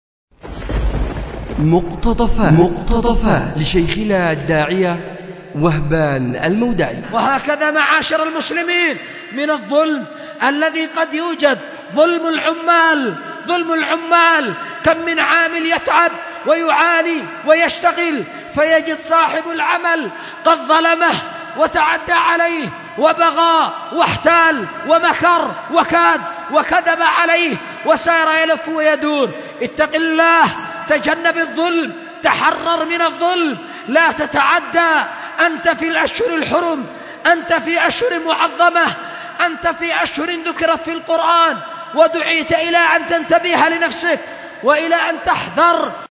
أُلقي بدار الحديث للعلوم الشرعية بمسجد ذي النورين ـ اليمن ـ ذمار